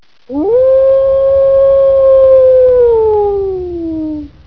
a_wolf.wav